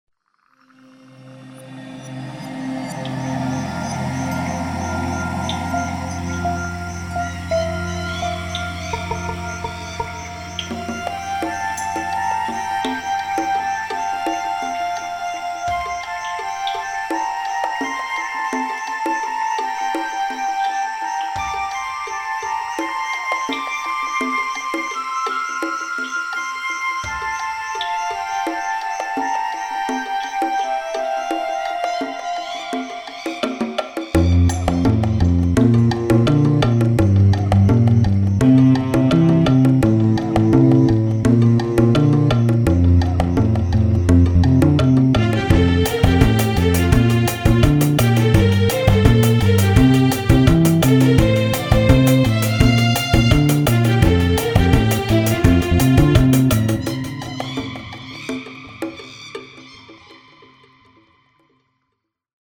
I like it, managed not to sound like a MIDI.